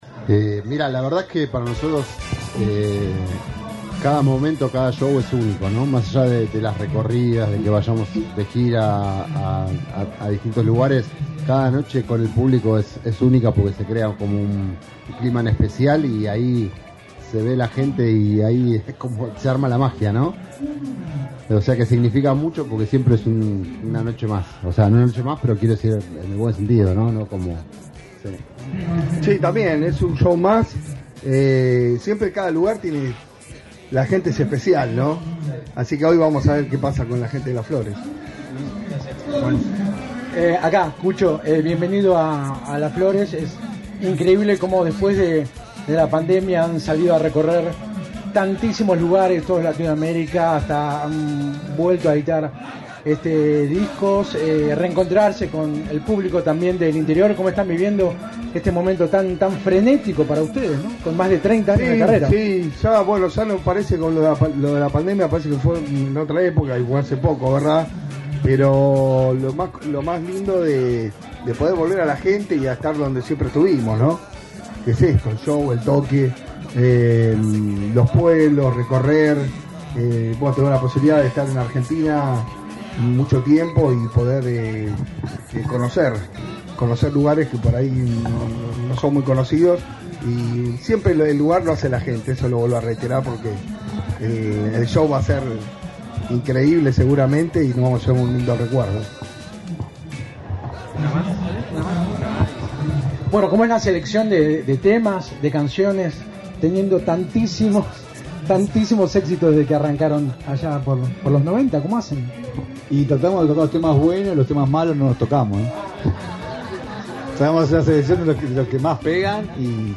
Conferencia de prensa Los Auténticos Decadentes: